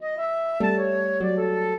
flute-harp
minuet1-5.wav